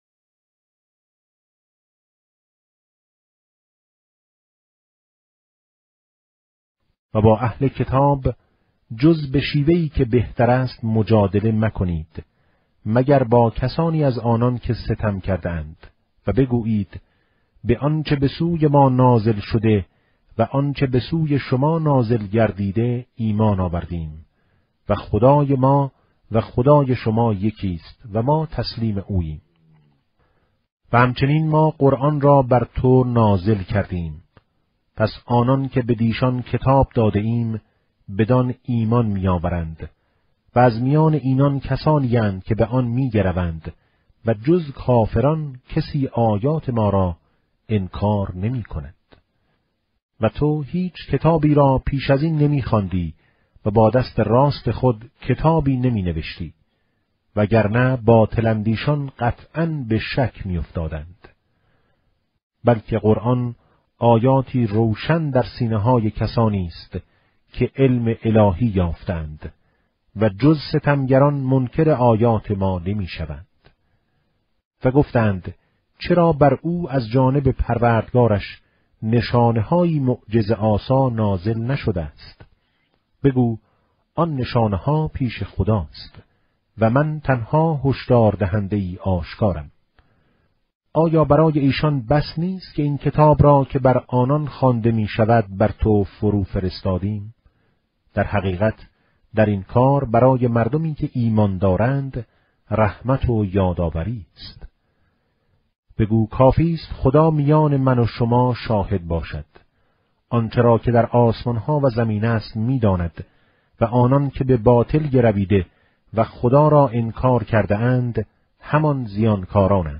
قرائت ترتیل جزء بیست‌ویکم قرآن با صدای قاریان بین‌المللی + صوت